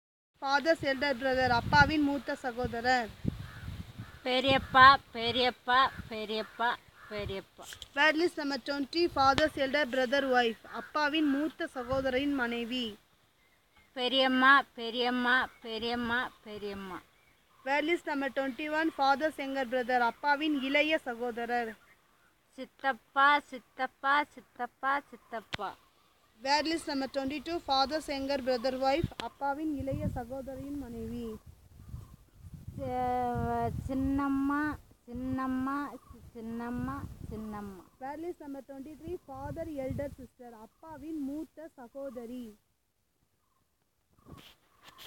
Elicitation of words about kinship terms - Part 2